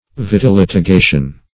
Search Result for " vitilitigation" : The Collaborative International Dictionary of English v.0.48: Vitilitigation \Vit`i*lit`i*ga"tion\, n. Cavilous litigation; cavillation.